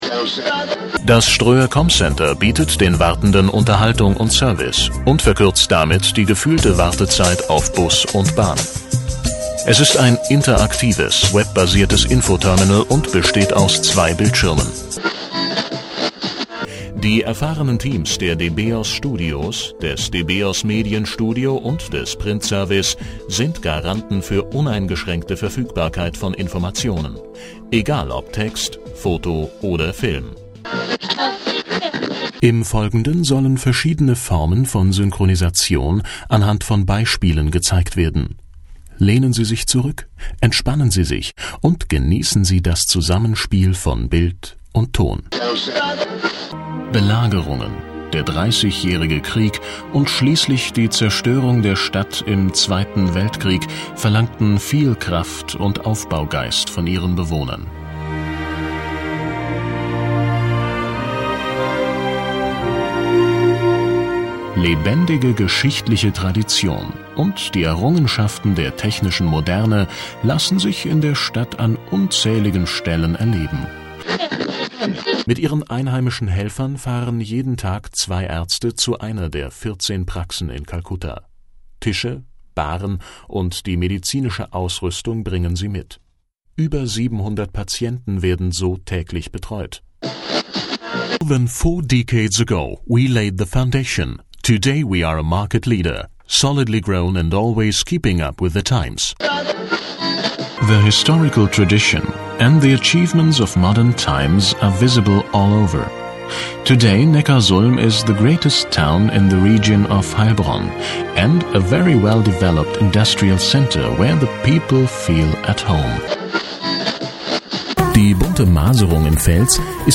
deutscher Sprecher für Imagefilme, Werbung, Werbesendungen, Spots, Comics und vieles mehr.
Sprechprobe: Werbung (Muttersprache):